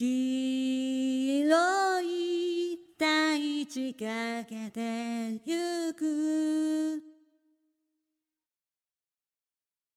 この状態から音程間を滑らかにつなげたり、ピッチのしゃくり上げ的なニュアンスを加えていきます。
ここではポルタメント的なニュアンスを追加していますが、長い音価のノートデータの発音中の変化なども加えることができます。
エモーションパラメーターを調整した後の歌唱表現は次のようになります。
vocal_edit_after.mp3